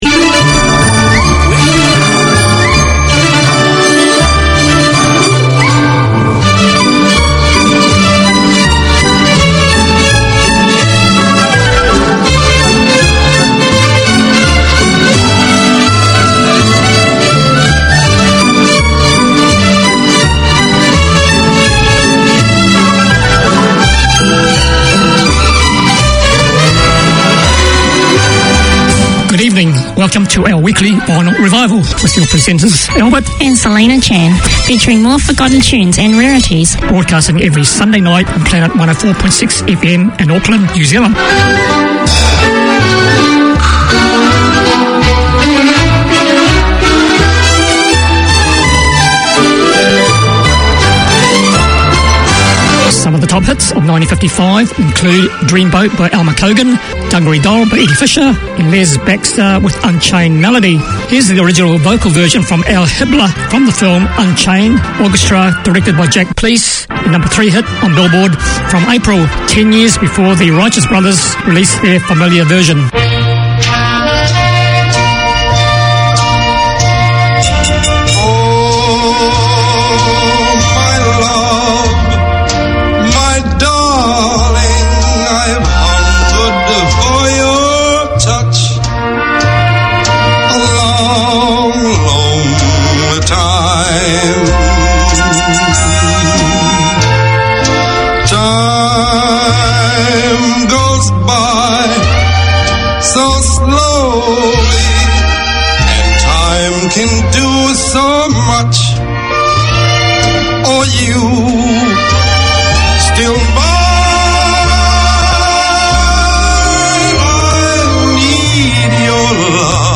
Listen for the best of the 30's,40's 50's,60's including hits and rarities.